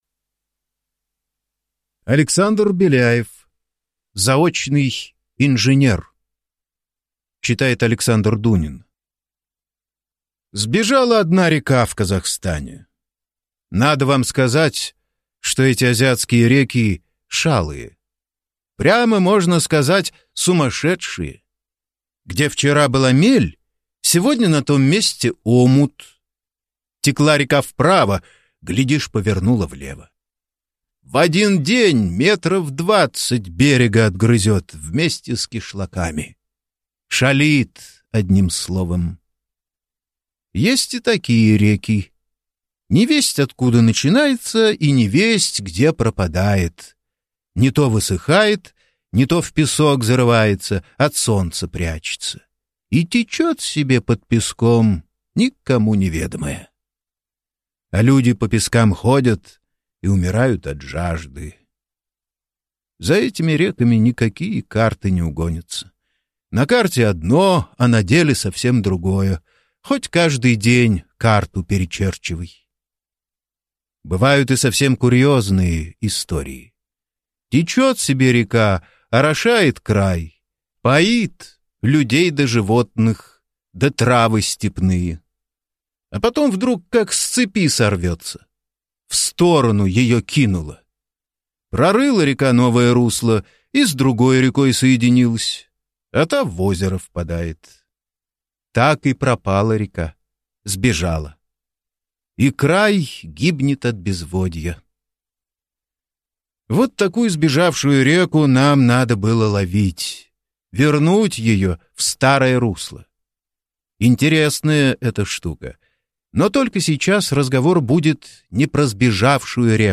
Аудиокнига Заочный инженер | Библиотека аудиокниг